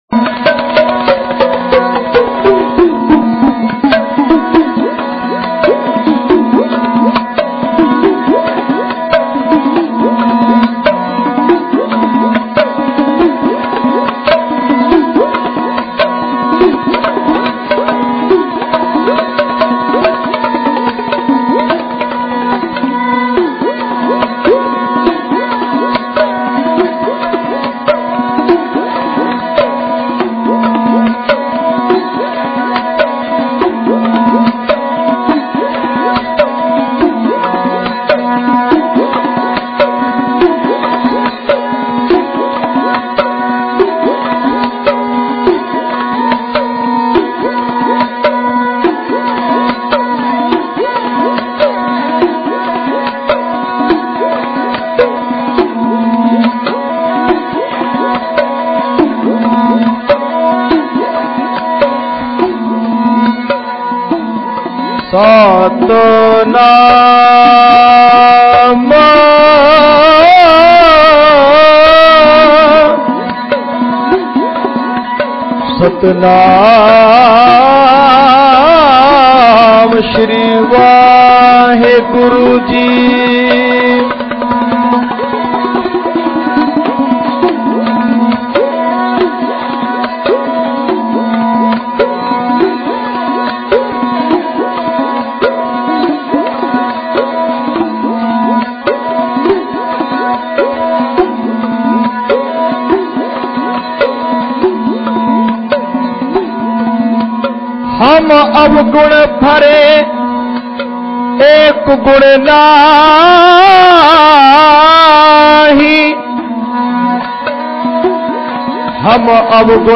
Genre: Dhadi Vaara Album Info